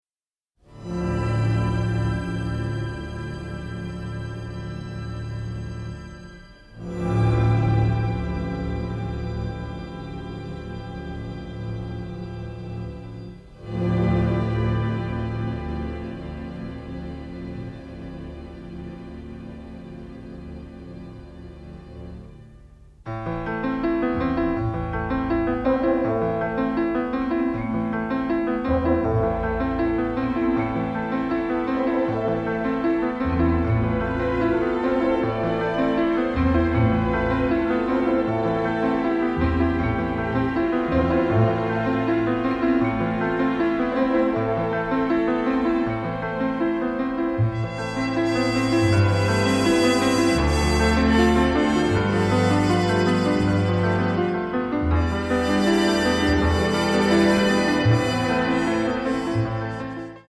Romantic and melancholic